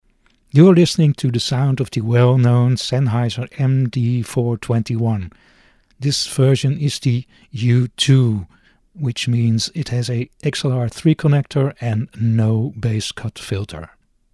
Listen to the sound of the MD 421
Sennheiser MD 421 U2 sound UK.mp3